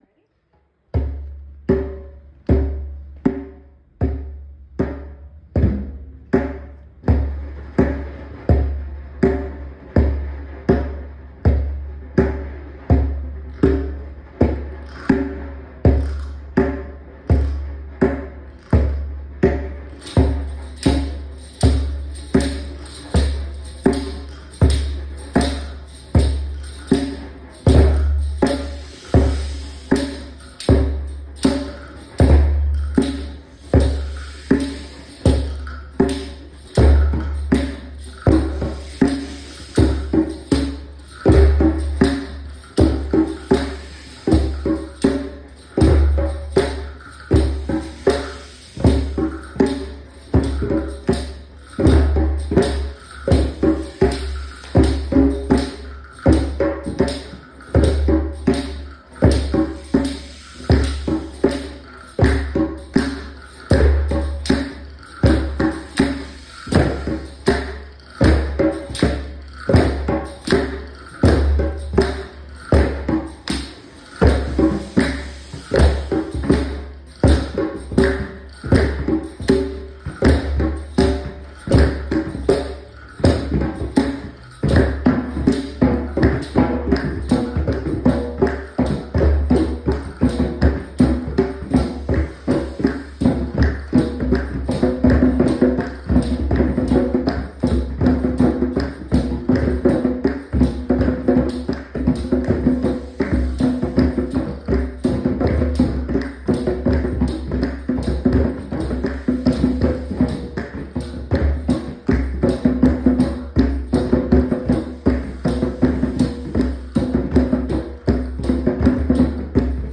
Rhythm ‘n Tea in the Park Drumming recordings
Thank you to everyone who came to our Mental Health Month event on October 23, we hope you all had a great day.